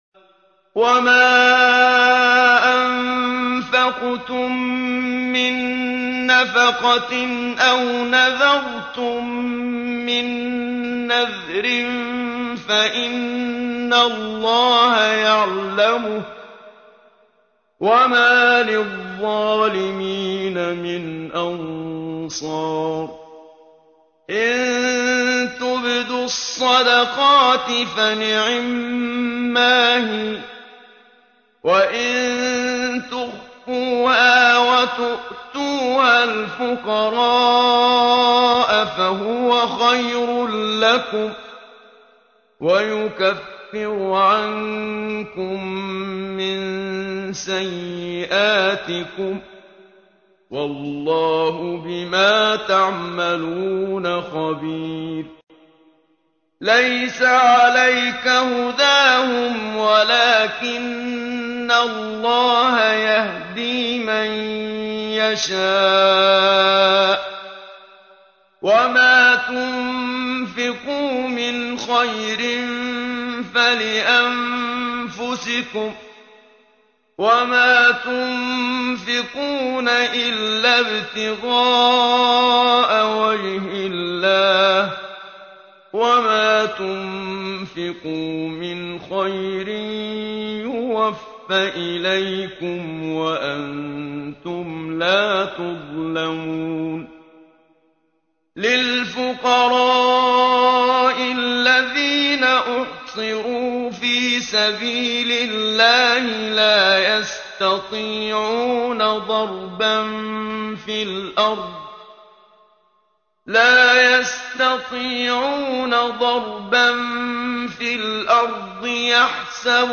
ترتیل صفحه 46 سوره مبارکه بقره(جزء سوم) از سری مجموعه صفحه ای از نور با صدای استاد محمد صدیق منشاوی